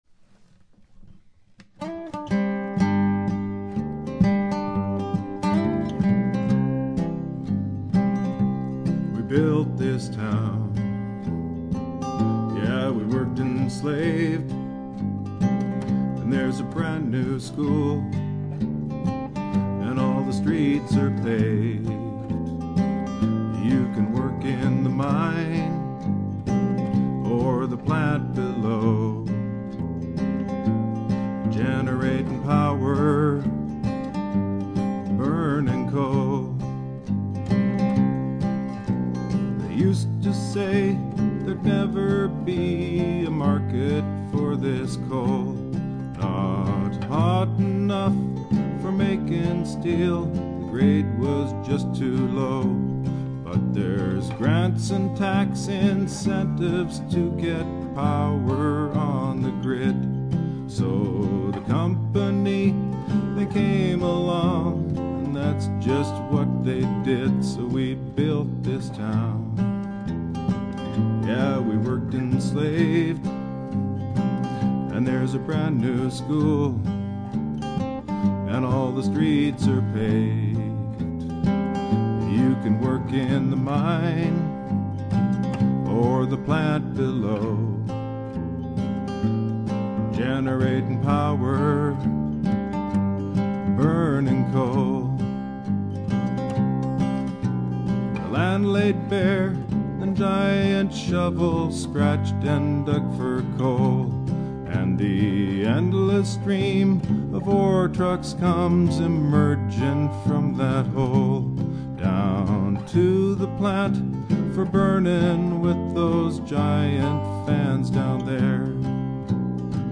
Guitar Bass, Vocals